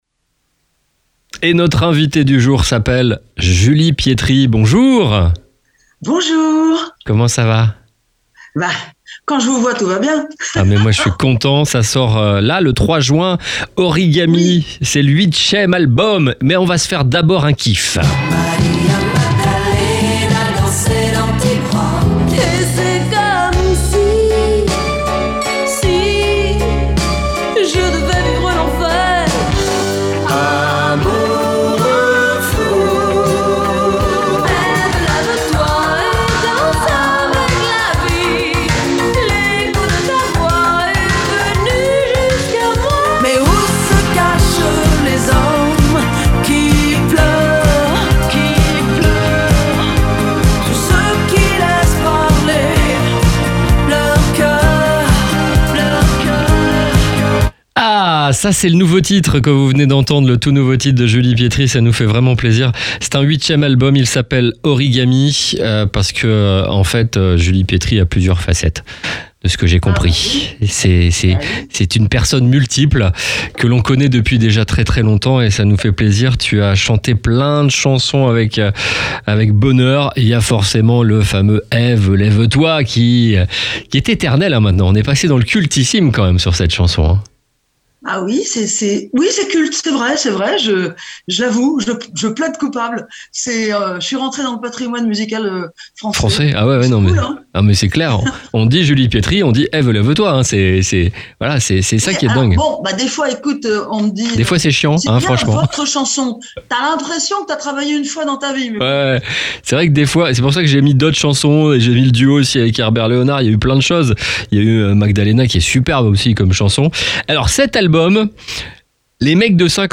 Julie Pietri était notre invité !